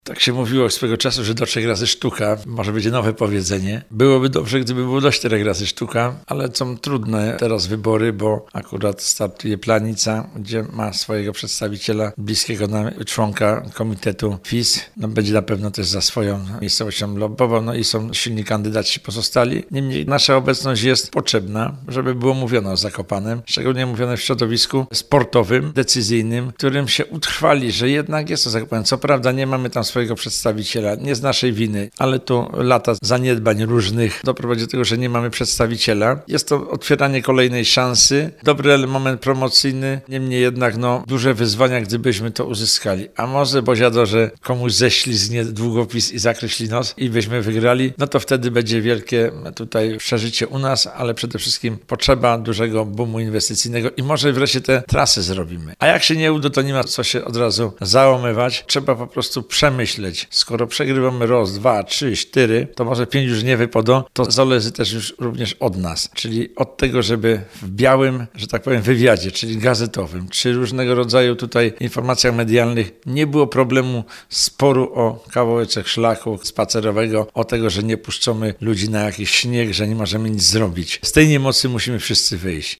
Andrzej Gąsienica Makowski starosta tatrzański
Posłuchaj: Andrzej Gąsienica Makowski starosta tatrzański o staraniach Zakopanego o FIS w 2017